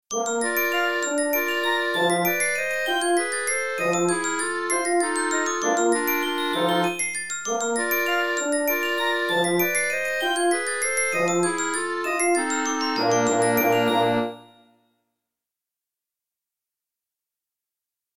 Listen to the variation for the bells.